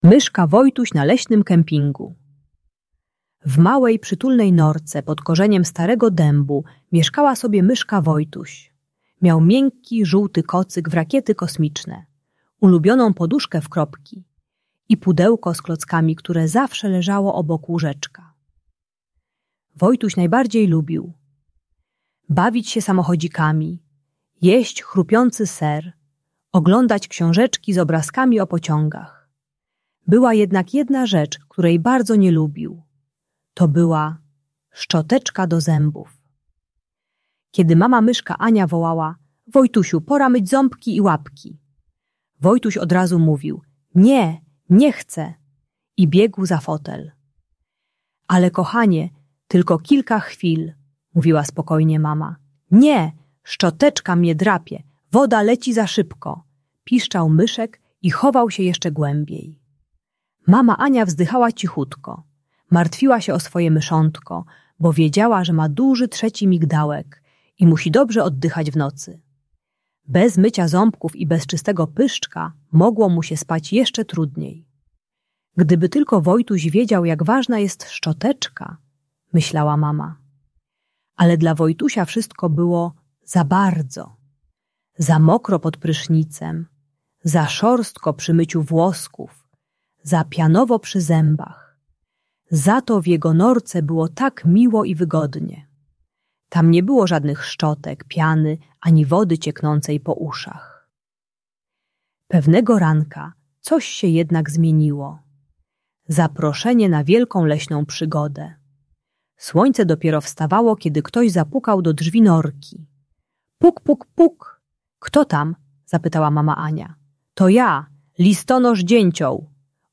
Przygody myszki Wojtusia - Problemy z jedzeniem | Audiobajka